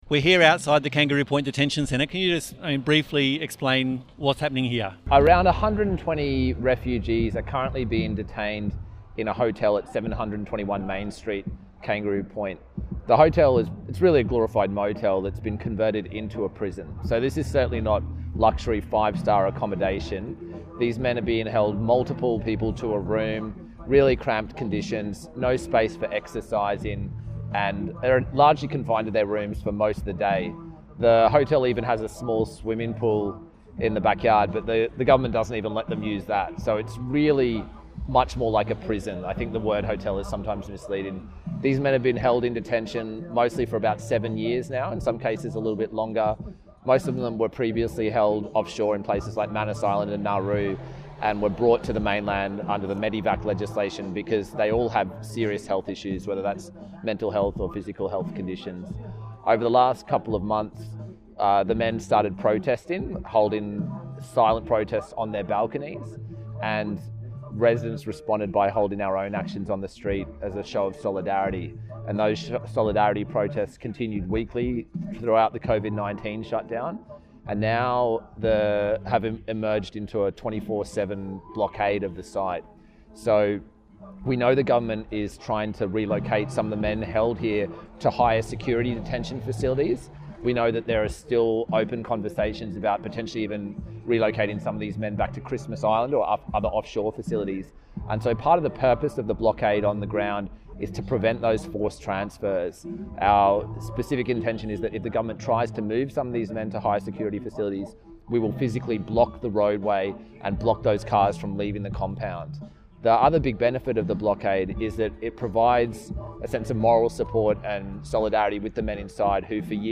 Interviews and Discussions
An interview and discussion with Greens Councillor Jonathan Sri about the refugees imprisoned at Kangaroo Point and the on-going campaign and struggle to free them.